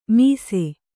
♪ mīse